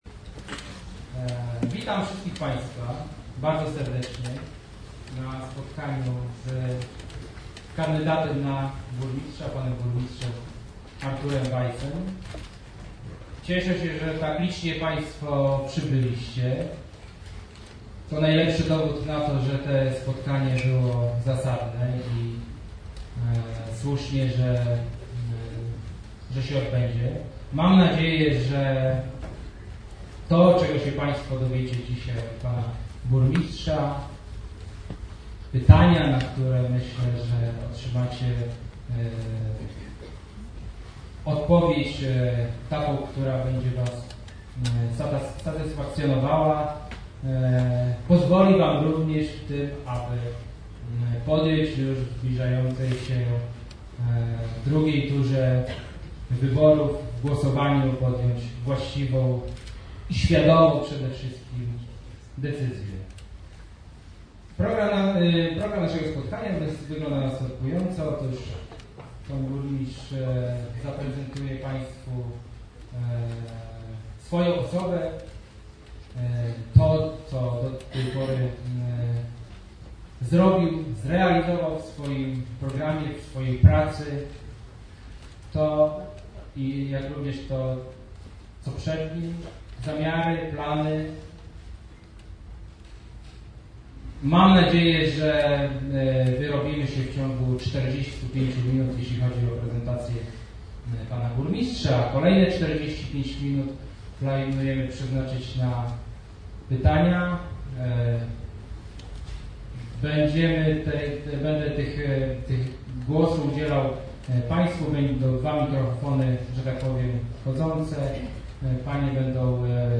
Spotkanie rozpoczął i prowadził poseł Cieśliński.
21 listopada w Szkole Muzycznej odbyło się spotkanie Burmistrza Artura Wajsa z mieszkańcami Lidzbarka.
Sala koncertowa szkoły muzycznej wypełniona po brzegi.
Spotkanie-Burmistrza-z-mieszkańcami.mp3